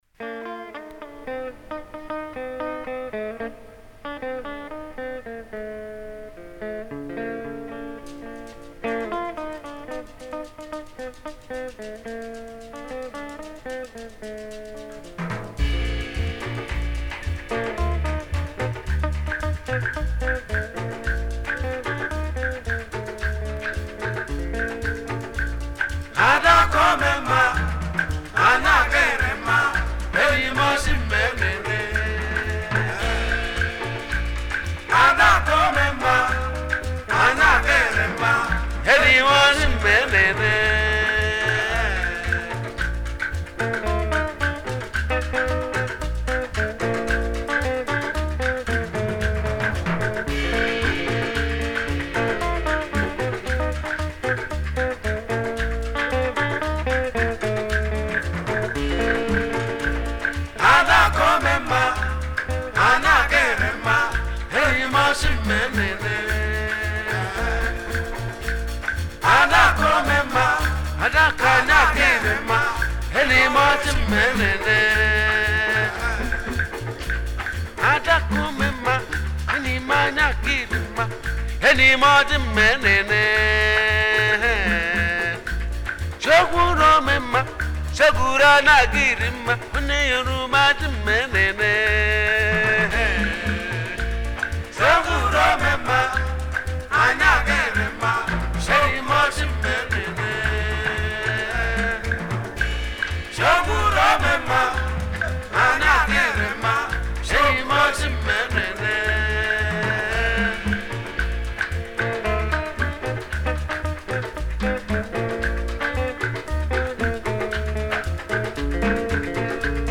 Highlife Music
Legendary Nigerian Igbo highlife singer and performer